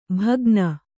♪ bhagna